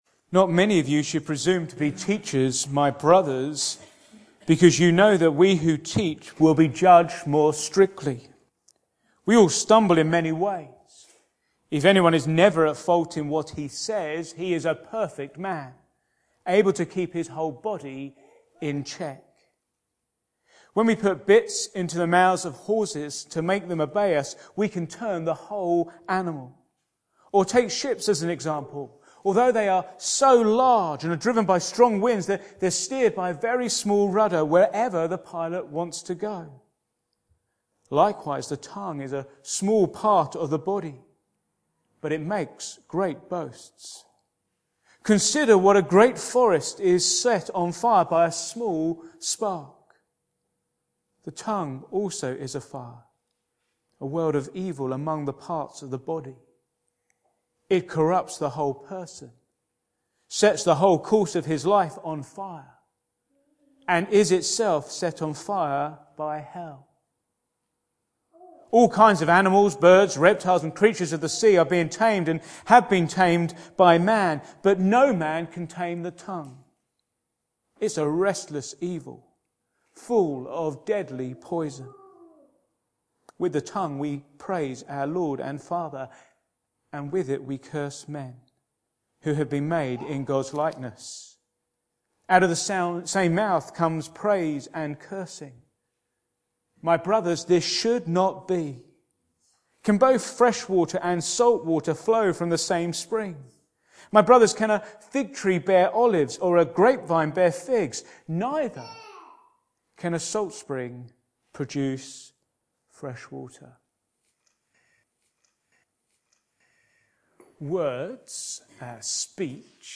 Back to Sermons A restless evil